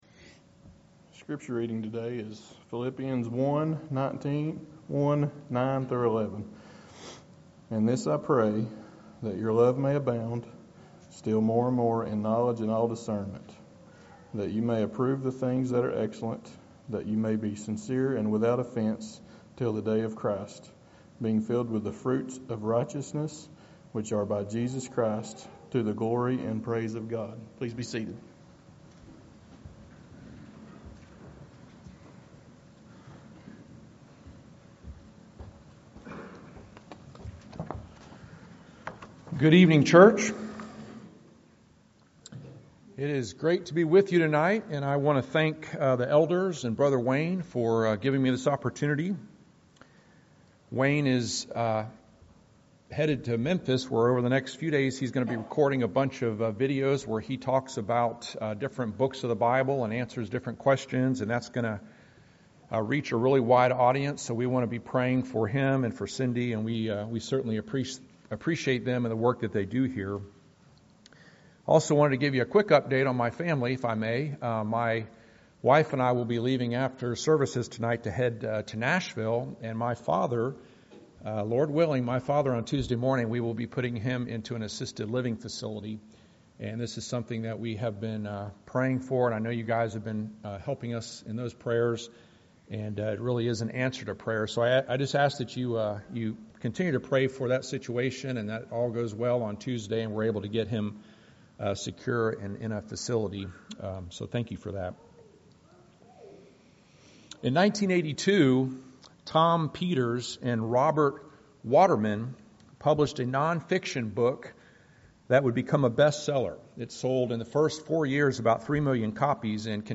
Philippians 1:9-11 Service Type: Sunday Evening « The Practice of the Church The Practice of the Church